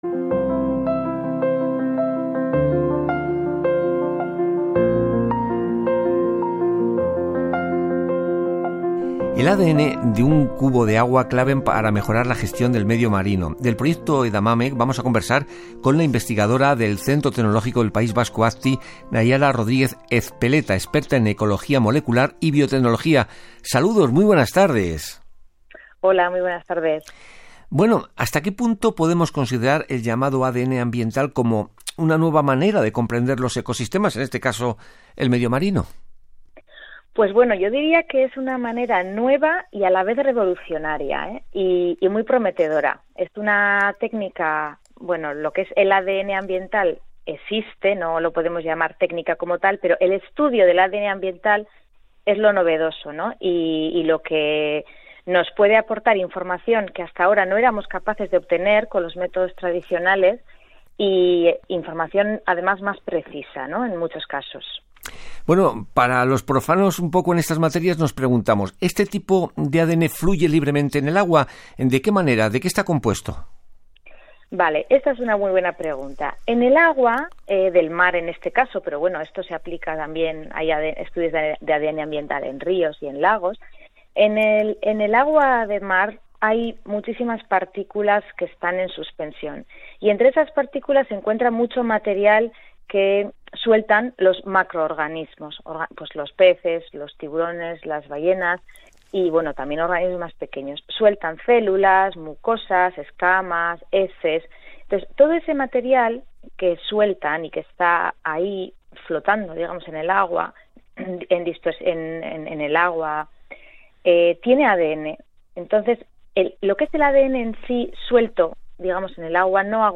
6 April 2022 – Radio Interview (program Españoles en la Mar, Radio Exterior) about environmental DNA (in Spanish)